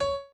b_pianochord_v100l8o6cp.ogg